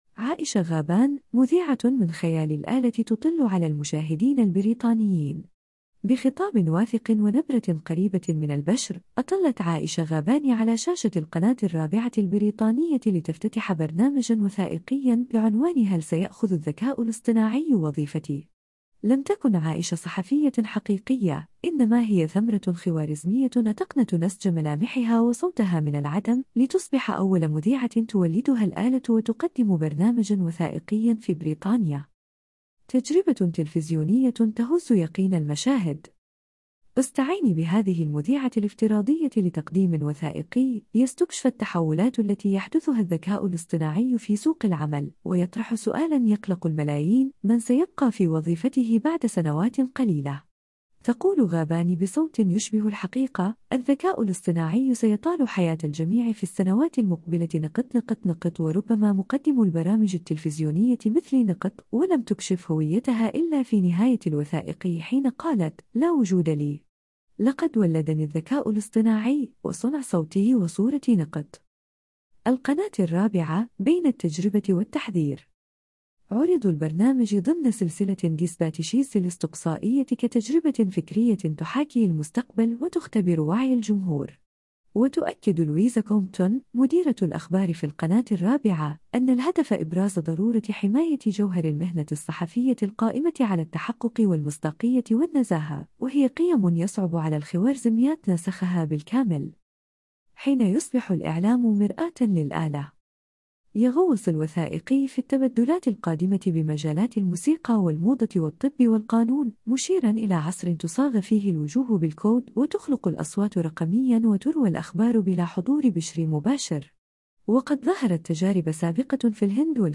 بخطاب واثق ونبرة قريبة من البشر، أطلت “عائشة غابان” على شاشة القناة الرابعة البريطانية لتفتتح برنامجًا وثائقيًا بعنوان “هل سيأخذ الذكاء الاصطناعي وظيفتي؟”. لم تكن عائشة صحفية حقيقية، إنما هي ثمرة خوارزمية أتقنت نسج ملامحها وصوتها من العدم، لتصبح أول مذيعة تولدها الآلة وتقدّم برنامجًا وثائقيًا في بريطانيا.